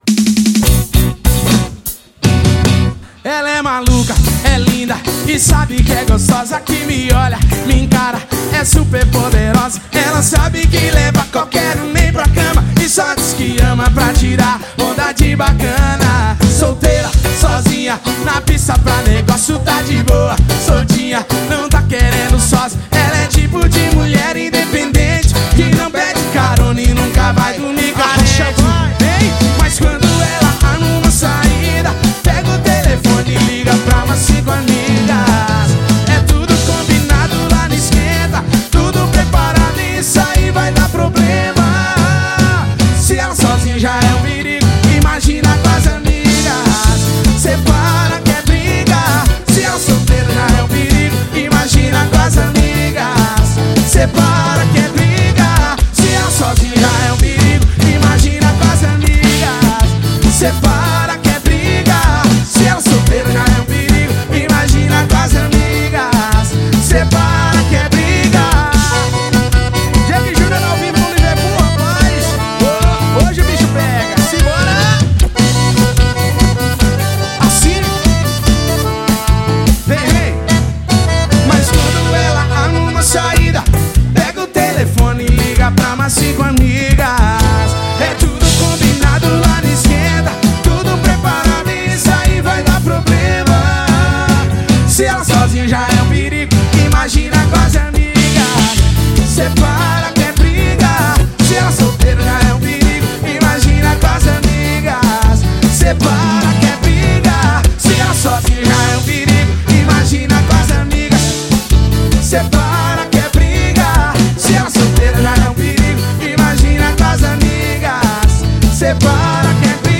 Cover.